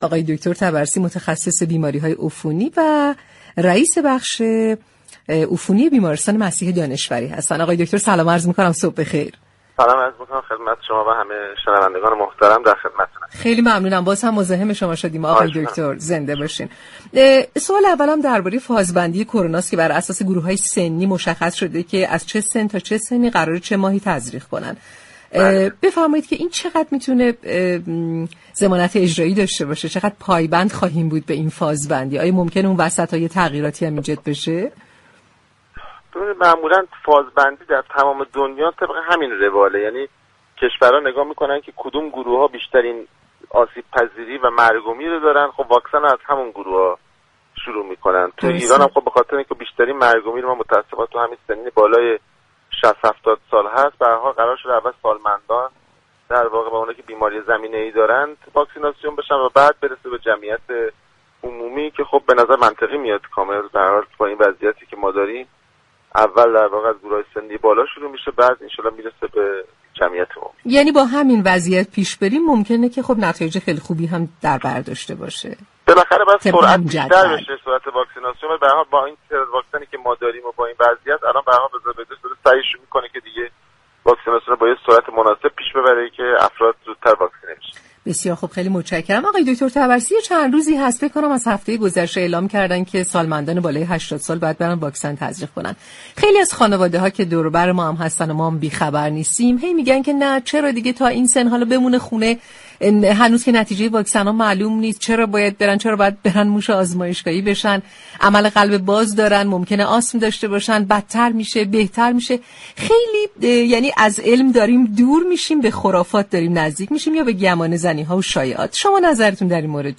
در گفتگو با برنامه «تهران ما سلامت»